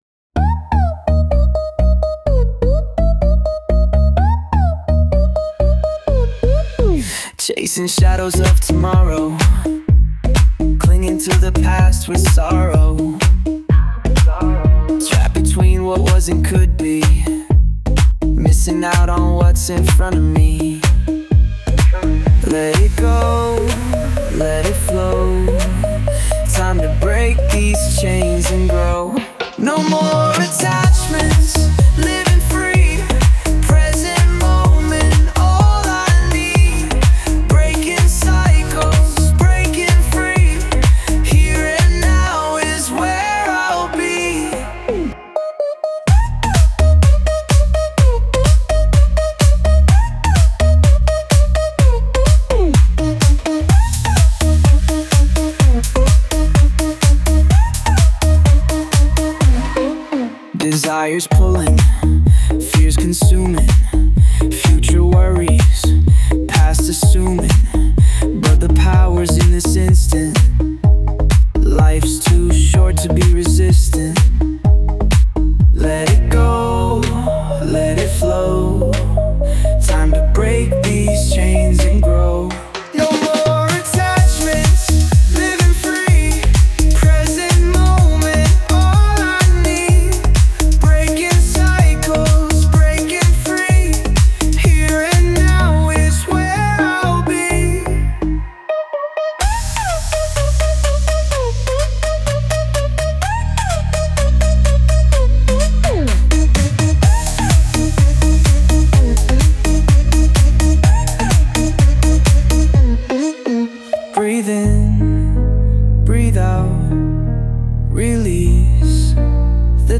MY_GAMES Musik produzieren Produkte entwickeln Crypto traden Chipwave & Chillbit-Artist Meine Musik verbindet elektronische Energie mit nostalgischem Chiptune- Charme und sanften Chillwave -Vibes. Jeder Track ist ein Zusammenspiel aus Gefühl und Melodie – mal verspielt, mal nachdenklich, aber immer authentisch.